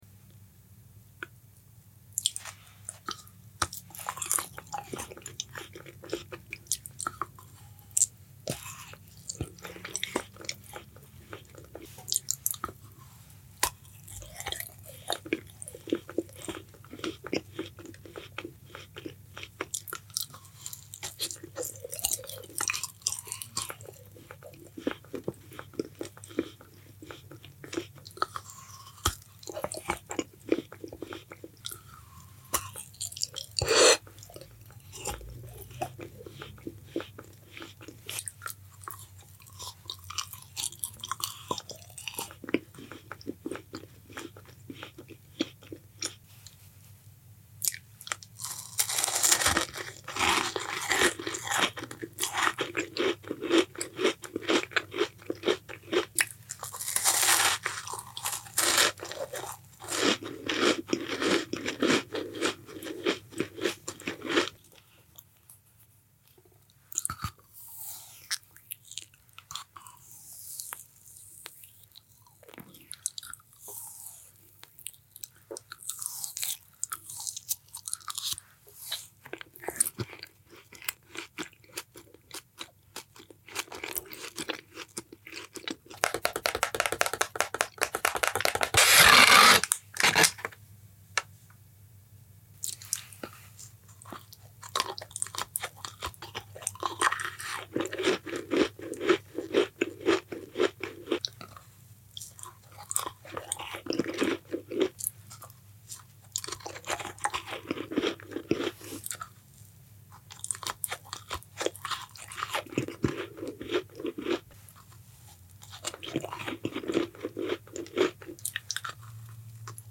ASMR Satisfying Eating Labubu Shaped